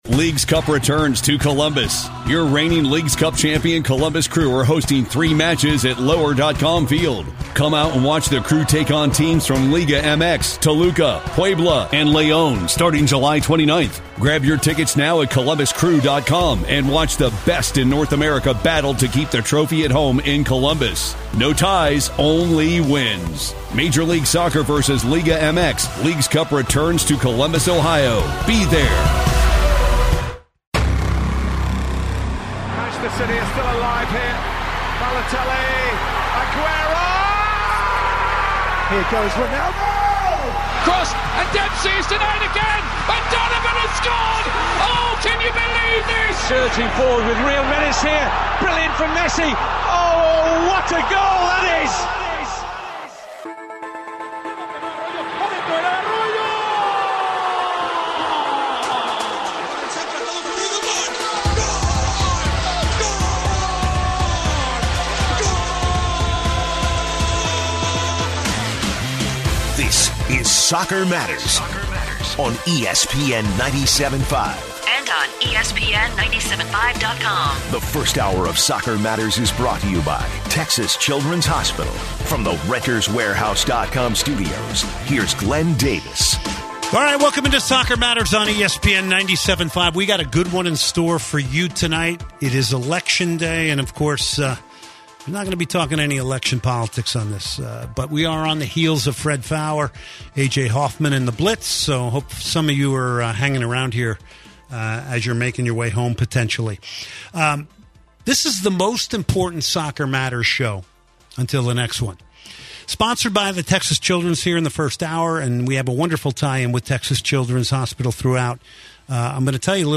He takes calls on the match and poses the question, what rivalry in sports is bigger than the Superclásico?